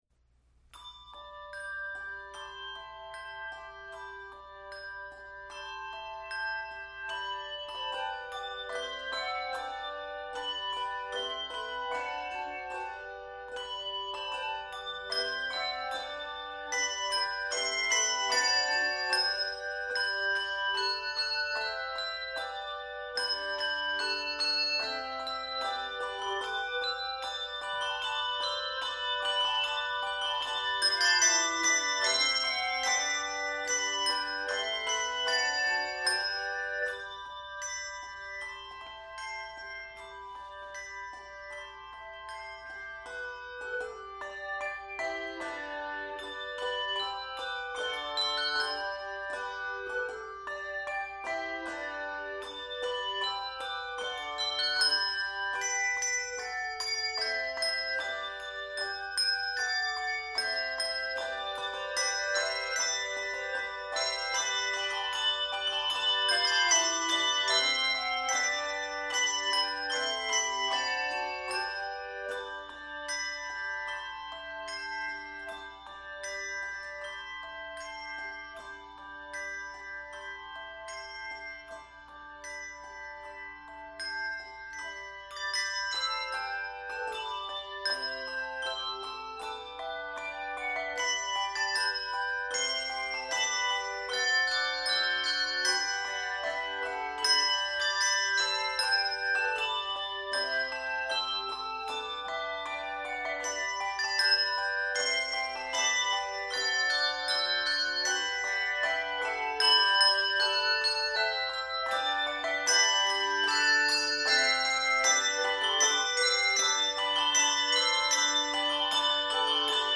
joyful and bright arrangement
Catalonian carol
Key of G Major.